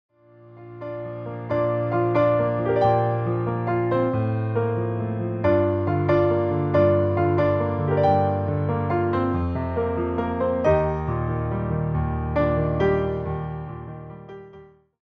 reimagined as solo piano arrangements.